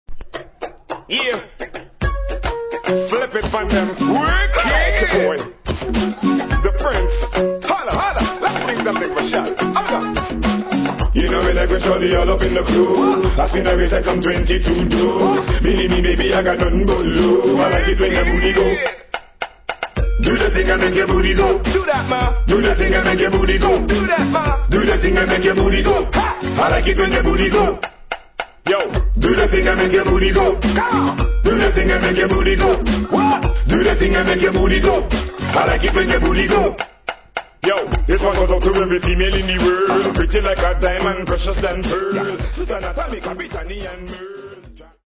TOP 10 DANCEHALL